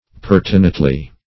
pertinately - definition of pertinately - synonyms, pronunciation, spelling from Free Dictionary Search Result for " pertinately" : The Collaborative International Dictionary of English v.0.48: Pertinately \Per"ti*nate*ly\, adv.